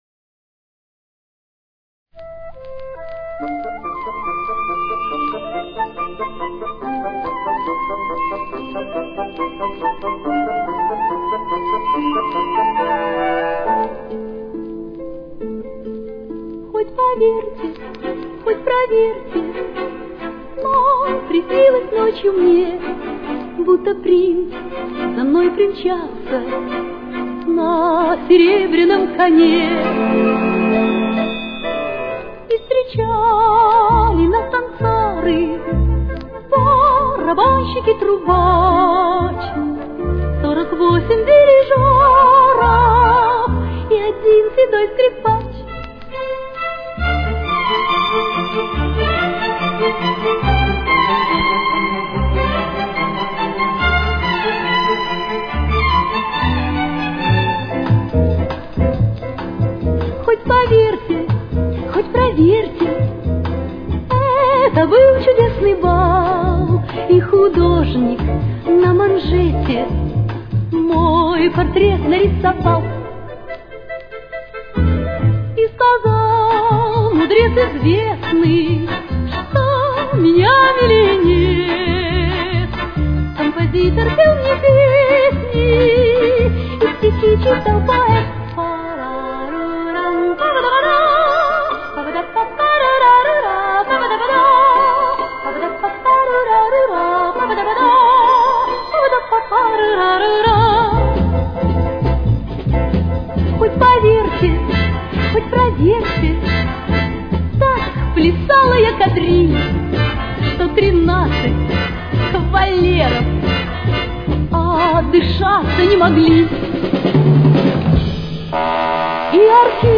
Темп: 140.